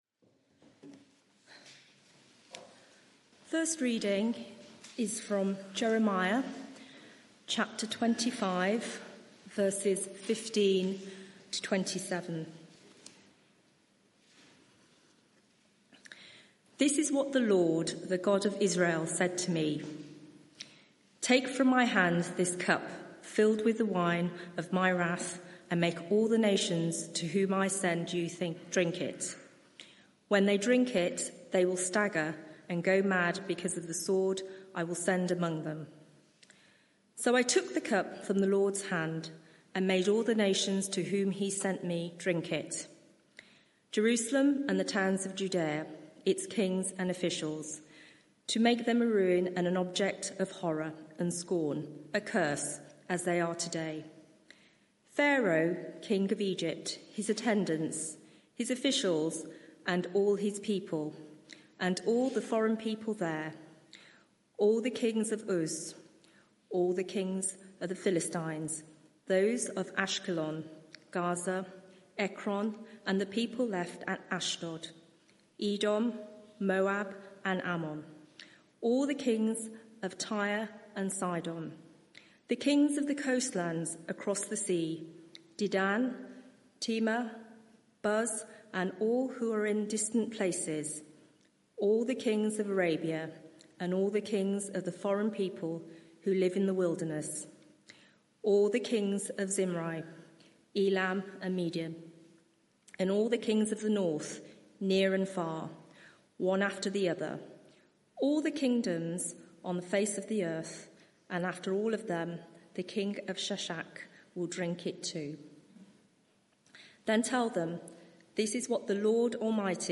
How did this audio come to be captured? Media for 6:30pm Service on Sun 16th Mar 2025 18:30 Speaker